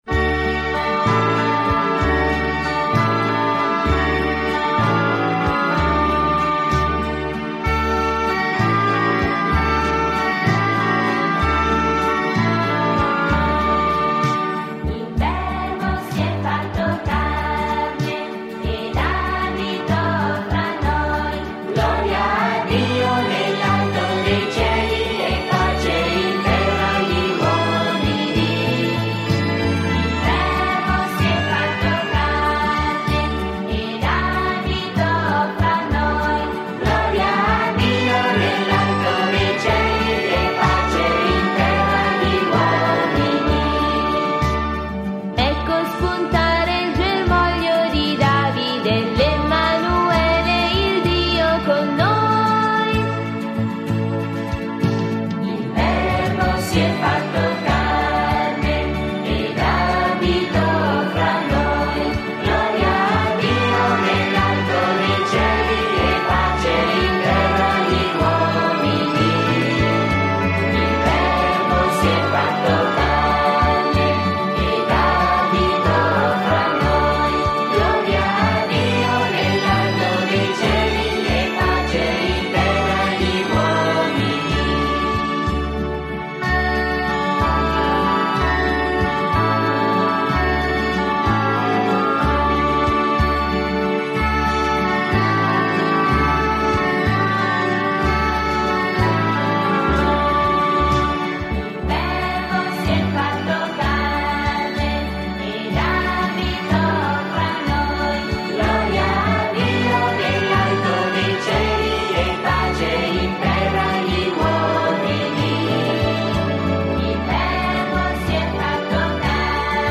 Canto per la Decina di Rosario e Parola di Dio: Il Verbo si è fatto carne